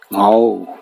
Cdo-fzho_5_(ngô).ogg